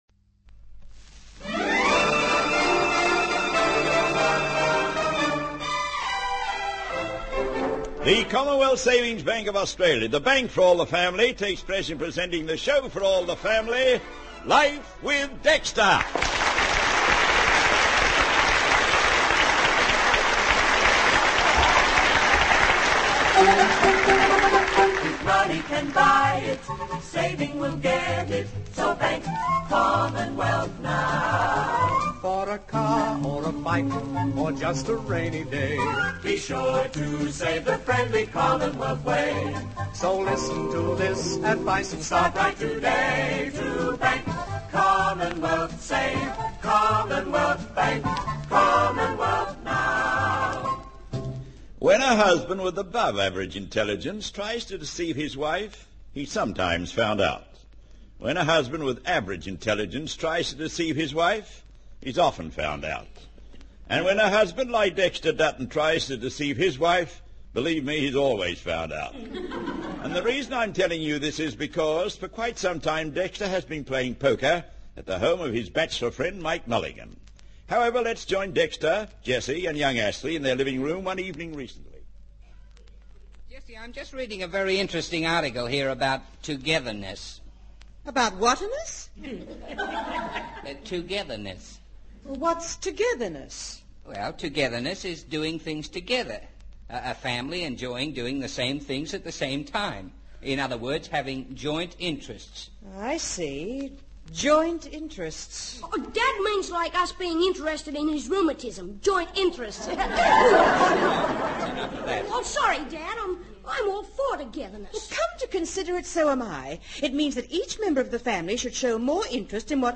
"Life with Dexter" was a popular Australian radio comedy program that aired from the 1950s through to the mid-1960s.